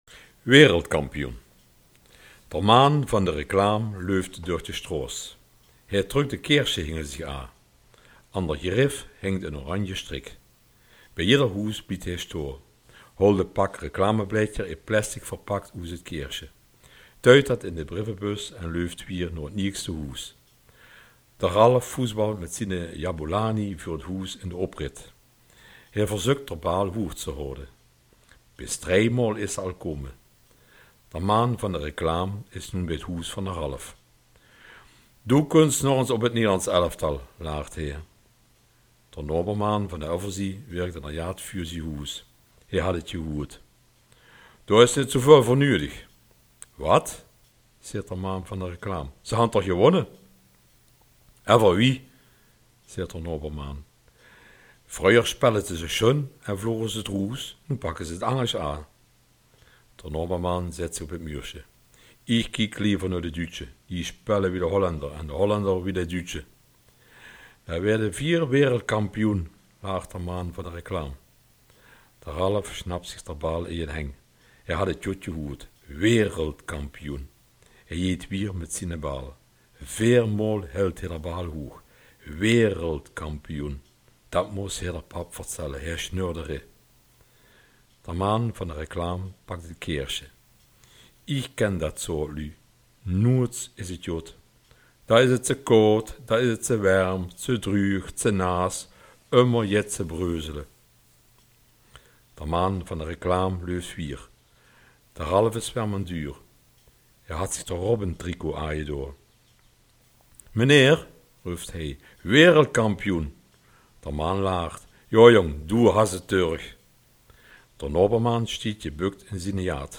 Wereldkampioen | Kerkraads Dialekt